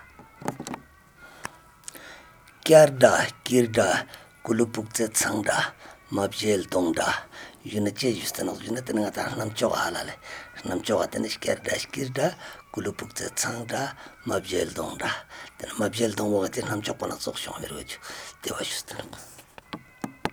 Retelling of a story in Beda - Part 10